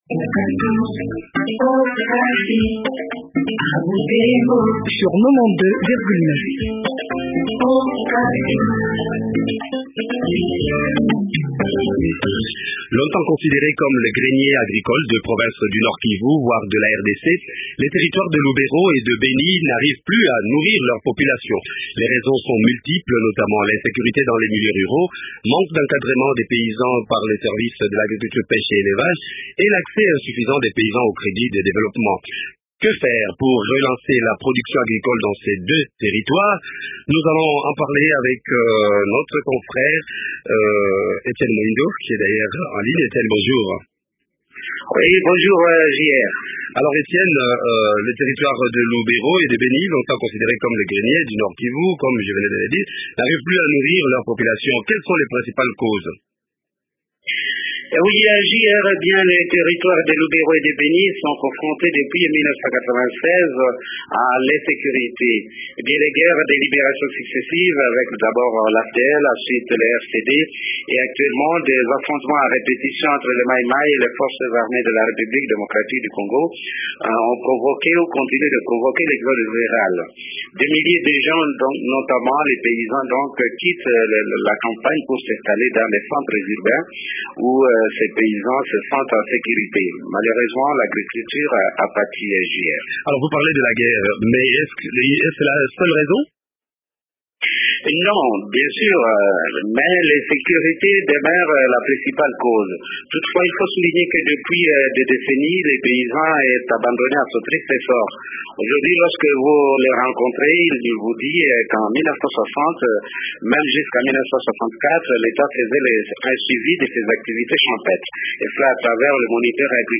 font le point avec le ministre provincial de l’agricultule, Bushoki jozia.